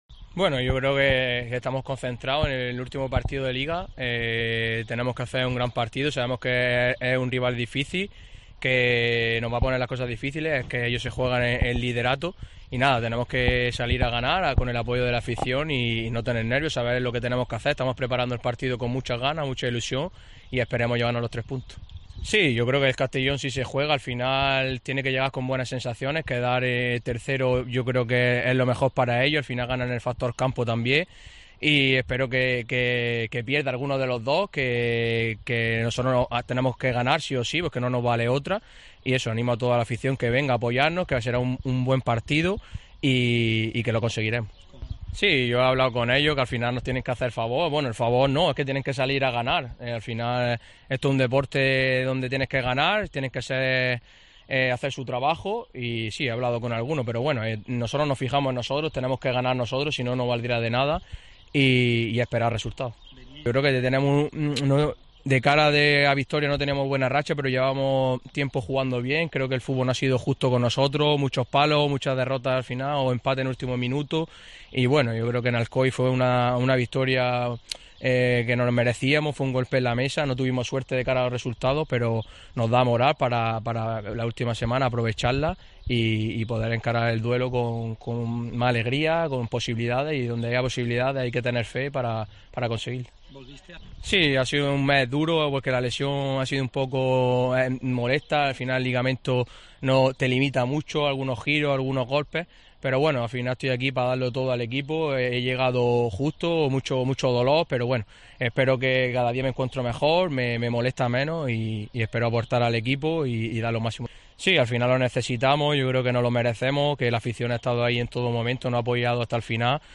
Dani Romera ha comparecido este miércoles para analizar el partido que le enfrenta este sábado al CD Eldense en el Enrique Roca Murcia, partido para el que ya hoy reservadas en torno a 20.000 localidades. Dani habló sobre el partido frente al conjunto alicantino, cómo se encuentra el jugador para este último encuentro, playoff y más.